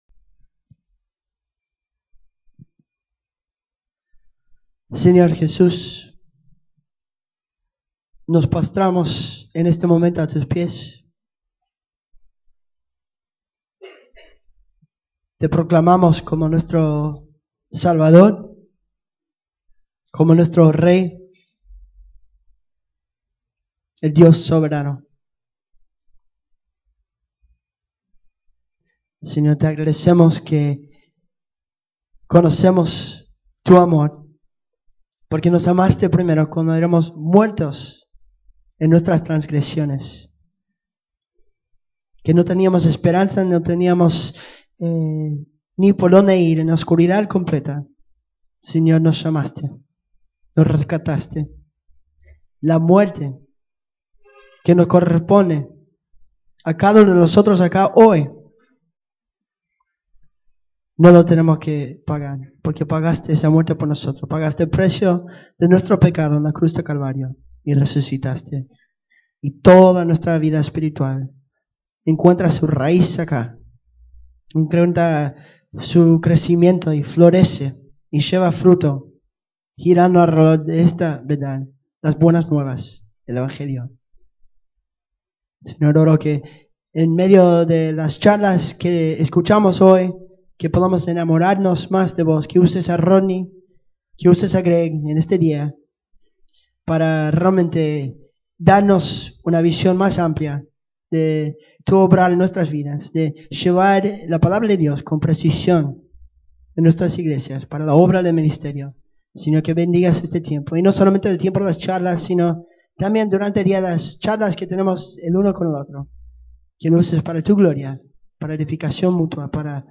septiembre 7, 2014 Sermón ¿Qué es la Consejería Bíblica?